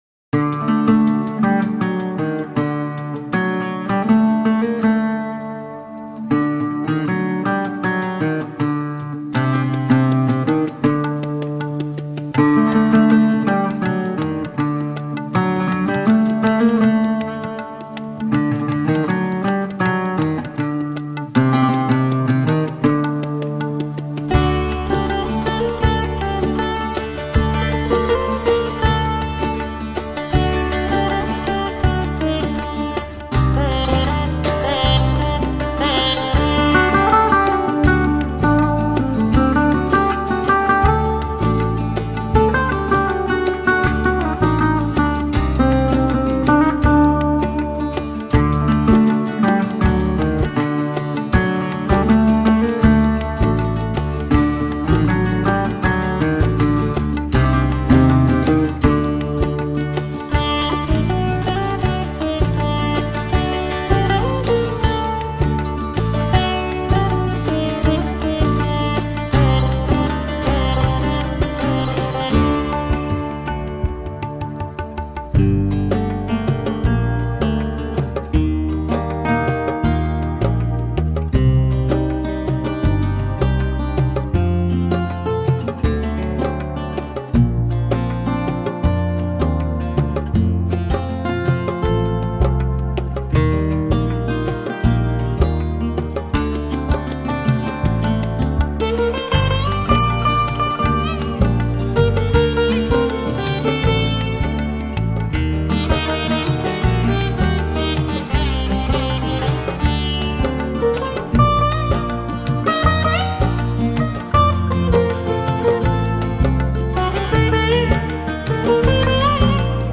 Genre: New Age, Instrumental, Flamenco